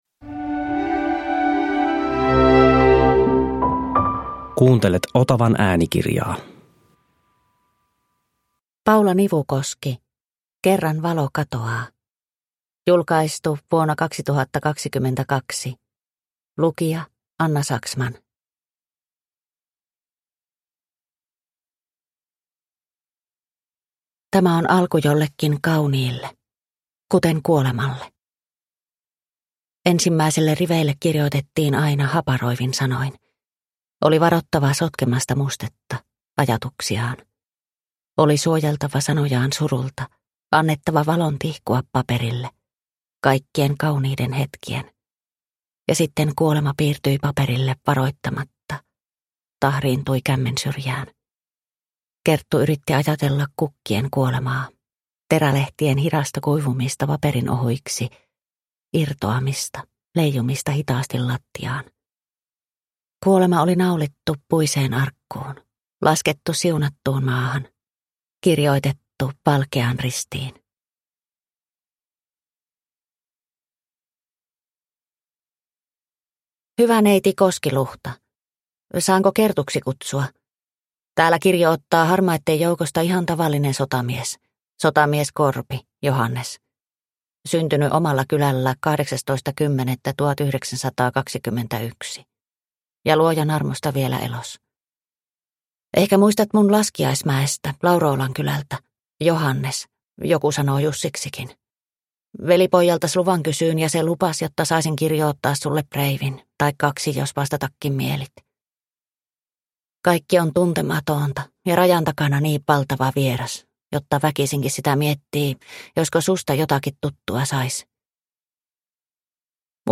Kerran valo katoaa – Ljudbok – Laddas ner